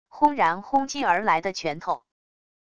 轰然轰击而来的拳头wav音频